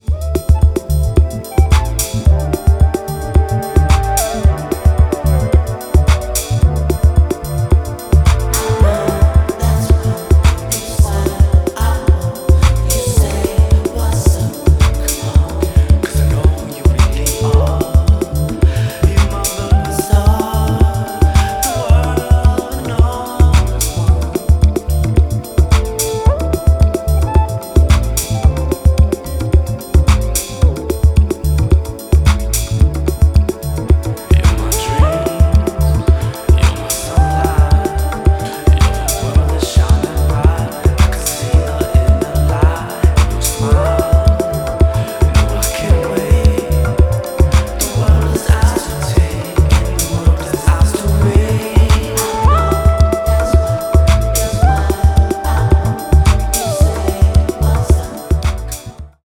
late-night coaster